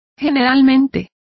Complete with pronunciation of the translation of ordinarily.